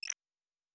pgs/Assets/Audio/Sci-Fi Sounds/Interface/Error 12.wav at 7452e70b8c5ad2f7daae623e1a952eb18c9caab4
Error 12.wav